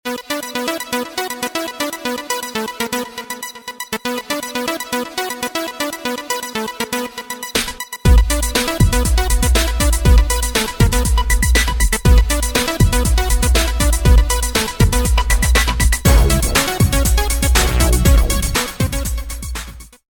KLINGELTÖNE HIP HOP/ELECTRO